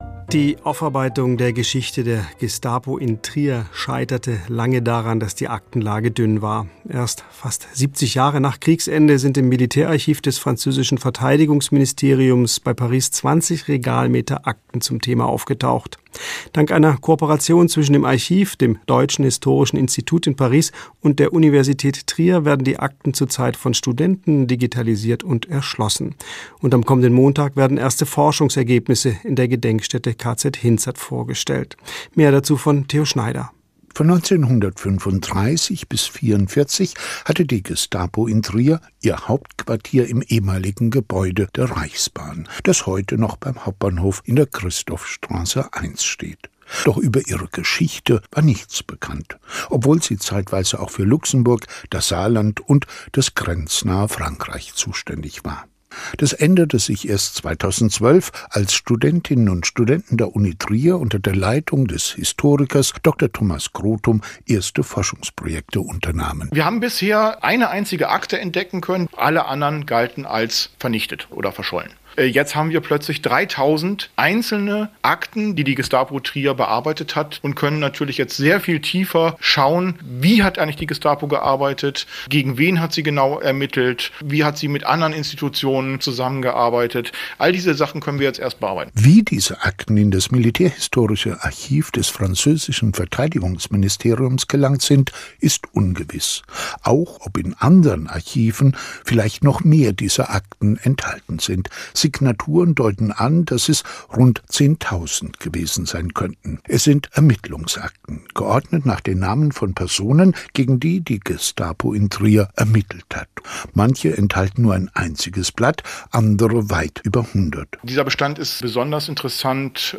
Radiobeitrag über die Kooperation mit dem französischen Militärarchiv in Vincennes (SHD) und dem Deutschen Historischen Institrut Paris (DHIP)